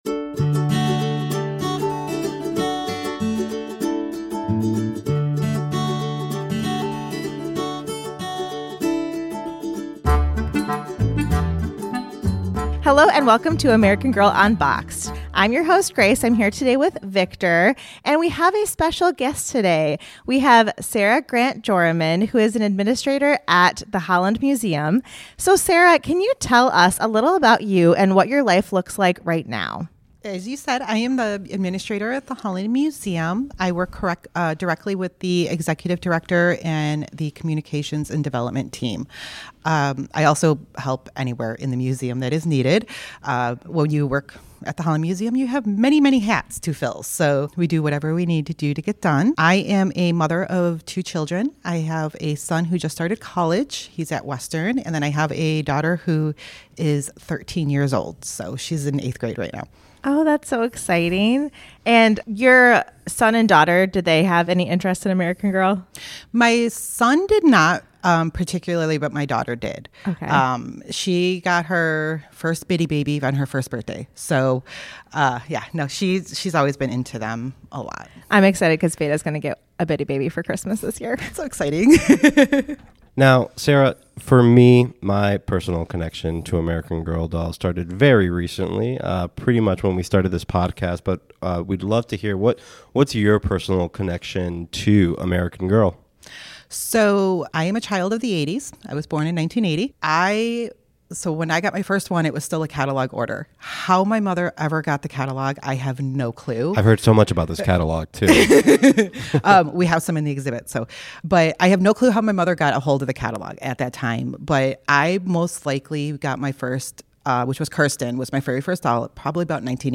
Or do you really just like nostalgic stories told in Midwestern accents?